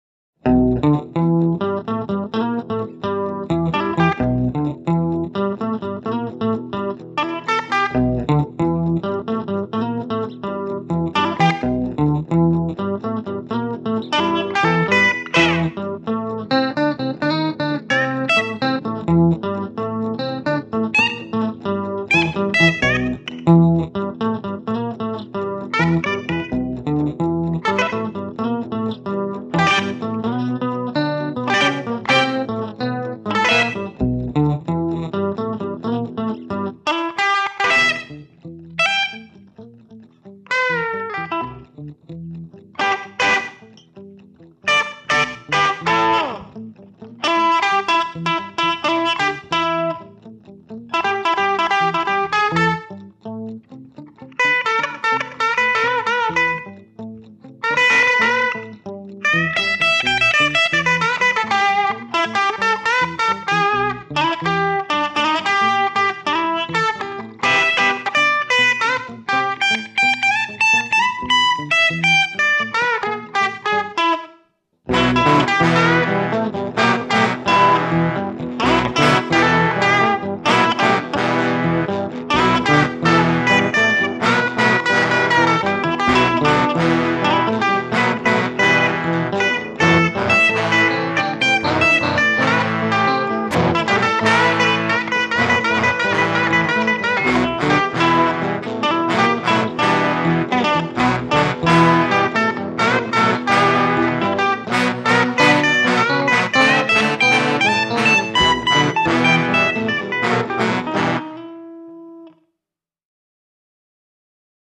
(instrumental)